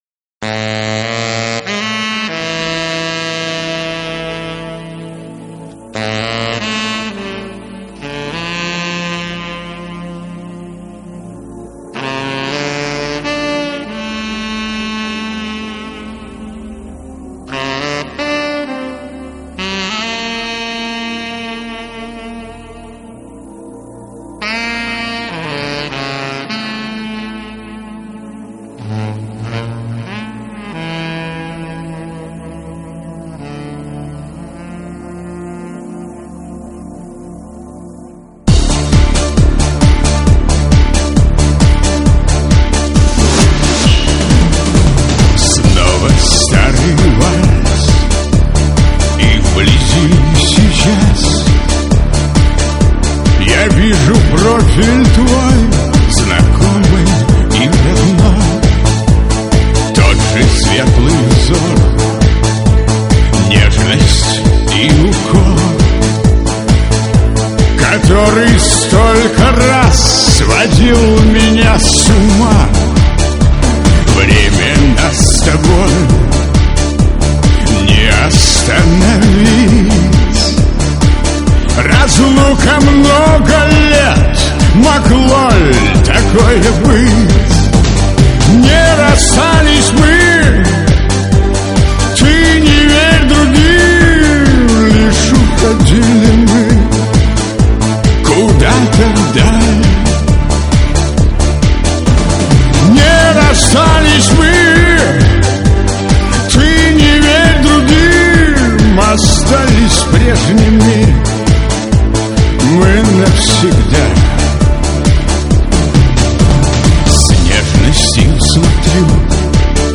К сожаленю более высокого качества нигде нет, а жаль....